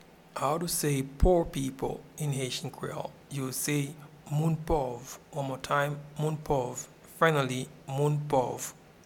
Pronunciation and Transcript:
Poor-people-in-Haitian-Creole-Moun-pov.mp3